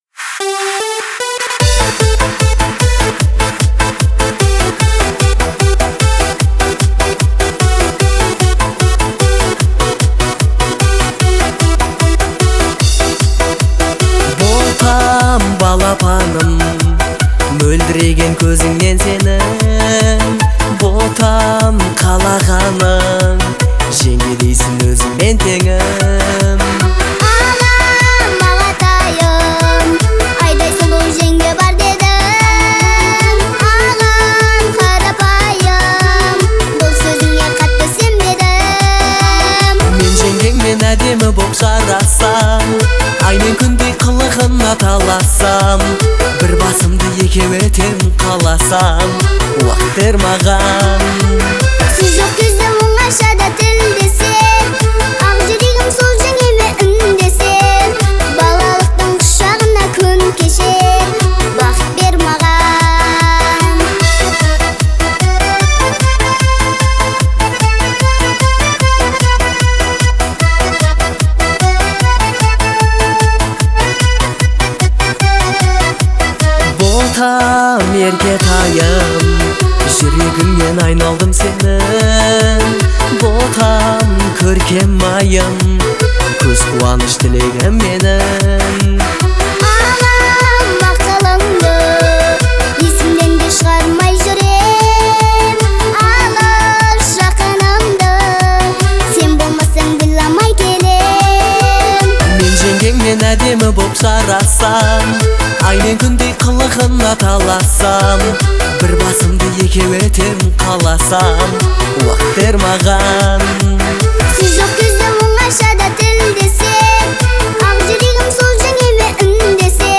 это трогательная песня в жанре казахской поп-музыки